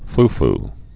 fu·fu or fu-fu
(ff)